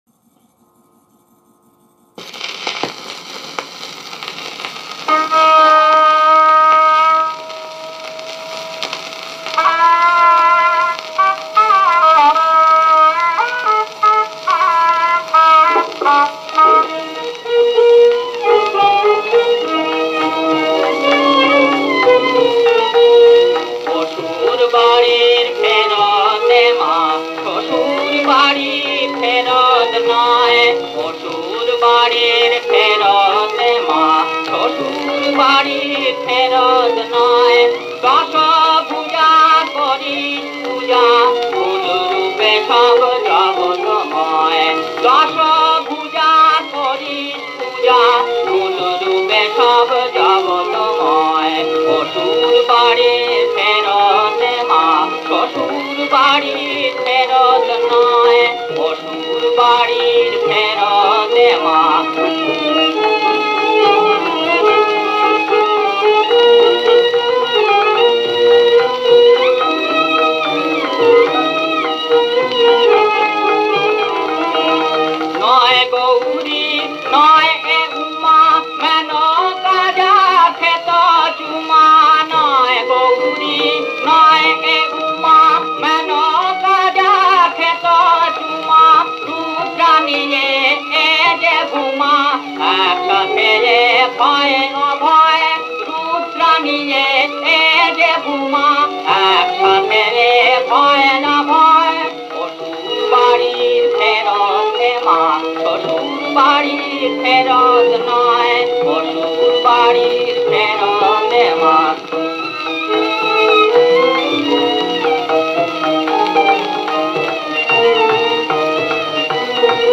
ভৈরবী-একতালা।